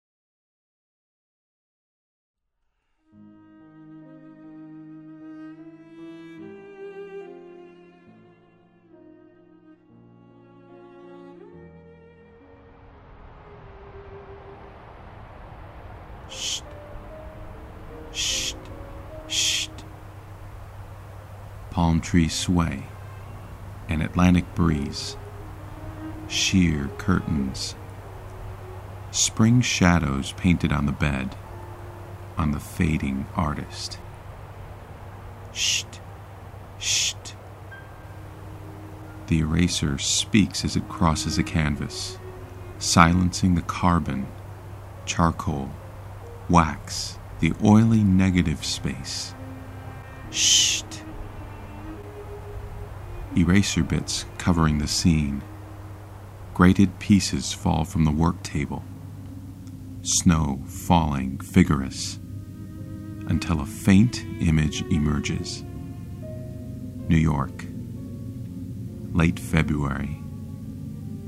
An Audio Short Story: Erasing DeKooning
My latest creation – an audio short story: